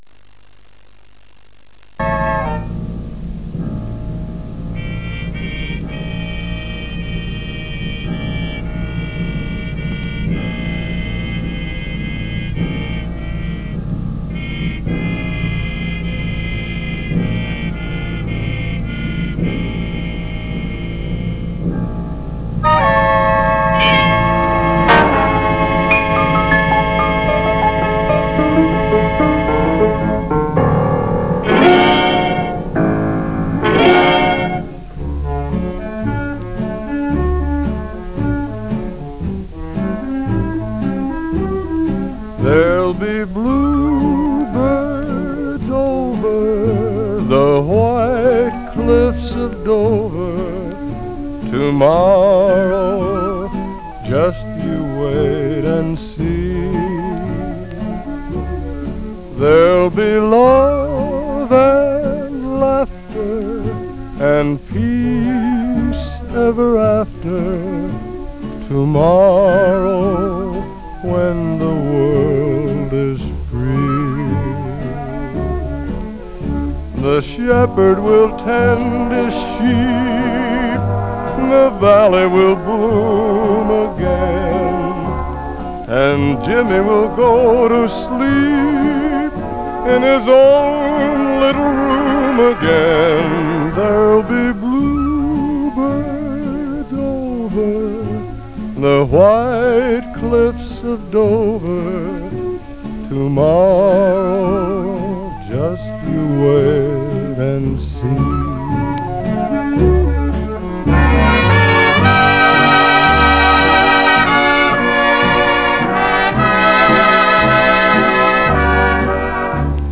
melodin i midiformat